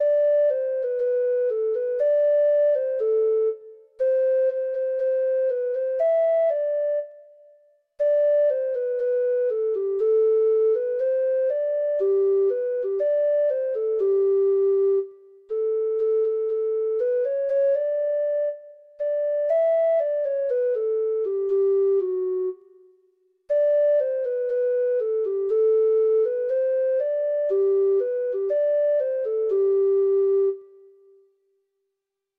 Traditional Trad. TAKE HENCE THE BOWL (Irish Folk Song) (Ireland) Treble Clef Instrument version
Irish